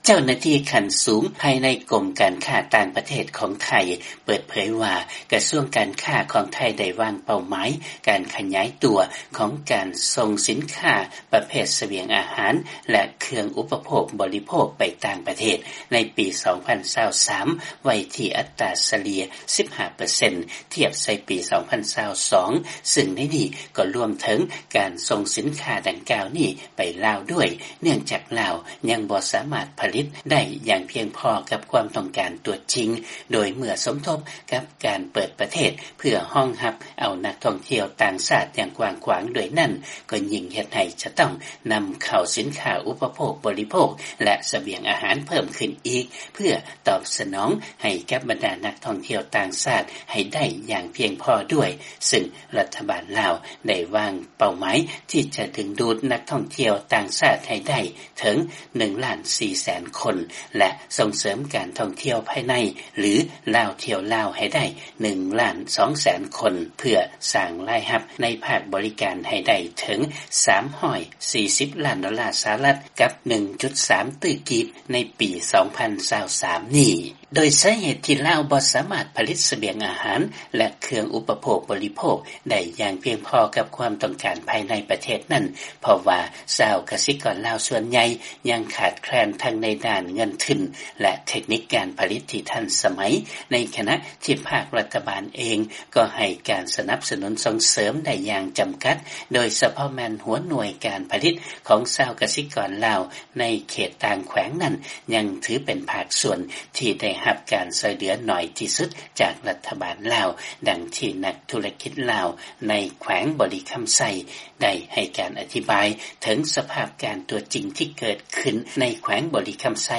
ຟັງລາຍງານ ການຜະລິດດ້ານສະບຽງອາຫານ ບໍ່ສາມາດຕອບສະໜອງຄວາມຕ້ອງການພາຍໃນ ຈຶ່ງເຮັດໃຫ້ລາວ ຕ້ອງເພິ່ງພາການນຳເຂົ້າຈາກຕ່າງປະເທດ